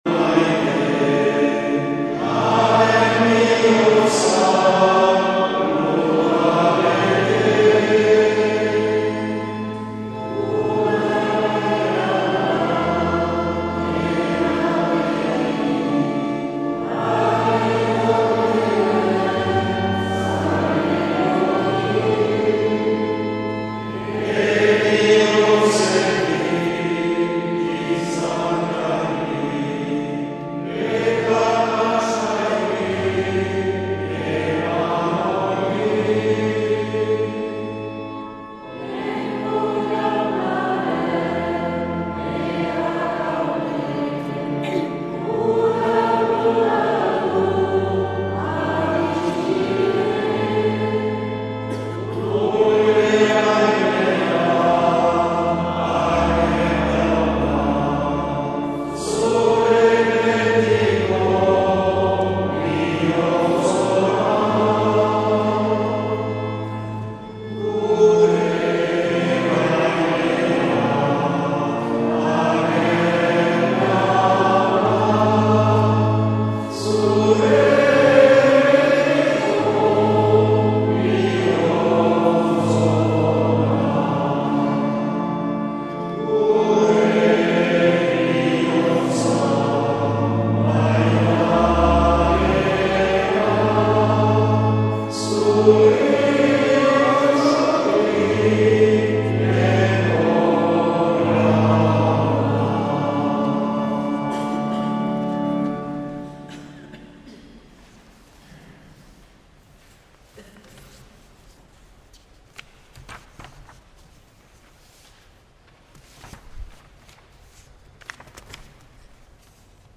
2026-03-01 Garizumako 2. Igandea - Donapaleu